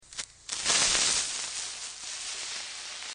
Minecraft Explosion Sound Button: Unblocked Meme Soundboard
Play the iconic Minecraft Explosion sound button for your meme soundboard!